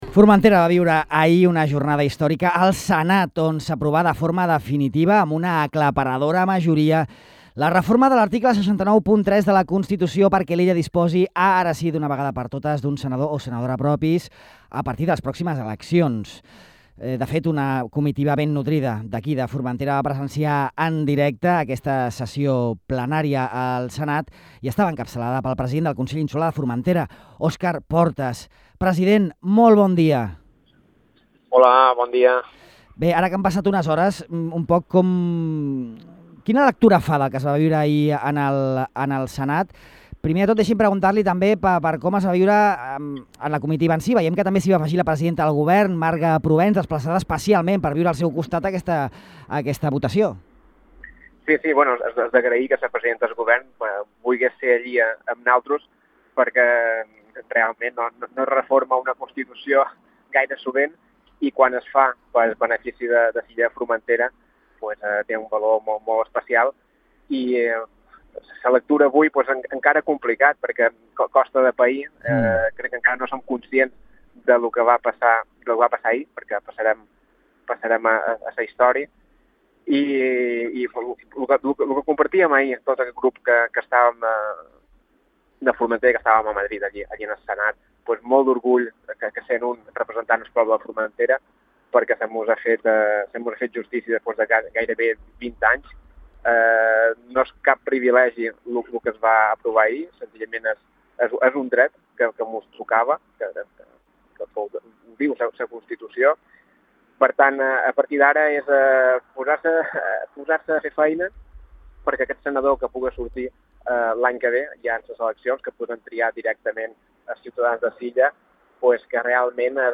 Podeu escoltar en aquest reproductor la intervenció d’Óscar Portas a Ràdio Illa.